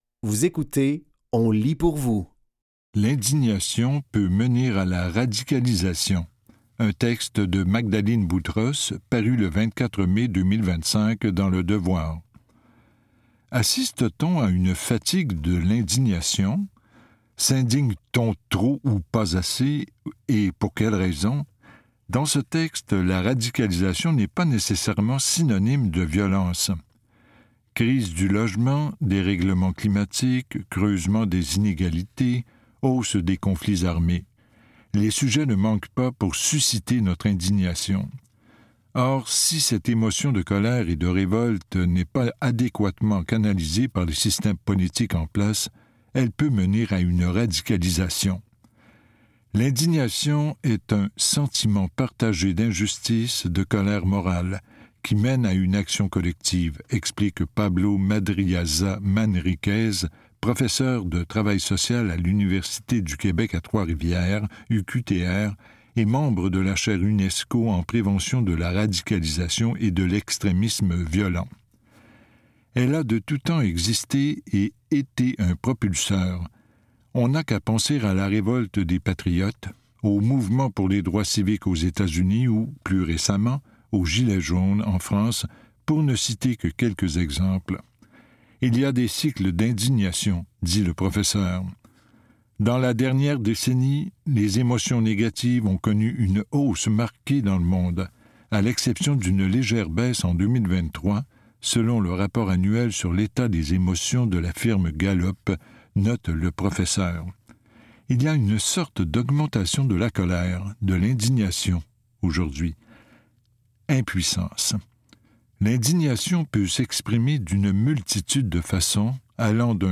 Dans cet épisode de On lit pour vous, nous vous offrons une sélection de textes tirés des médias suivants: Le Devoir et Les Libraires.